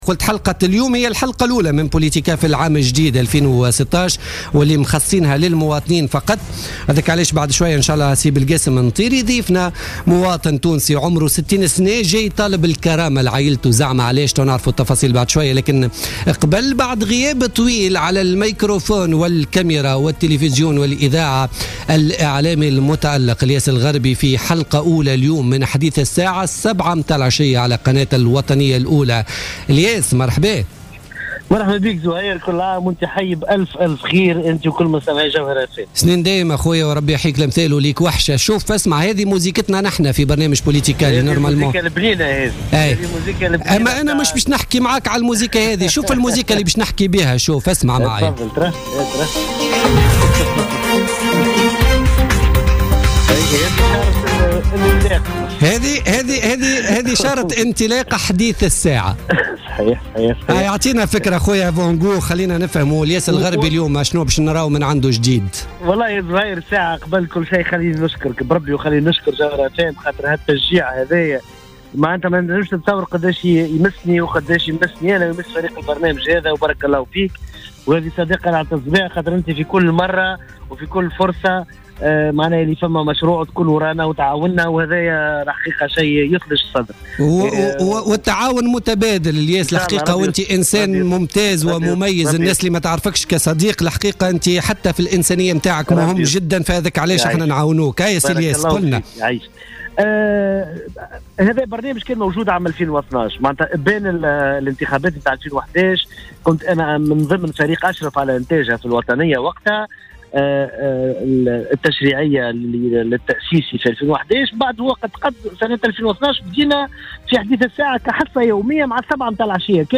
أعلن الإعلامي الياس الغربي في مداخلة له في بوليتيكا اليوم الإثنين 4 جانفي 2016 أن أول حصة من برنامجه الجديد "حديث الساعة" ستبث بدءا من مساء اليوم على الساعة السابعة مساء على الوطنية الأولى .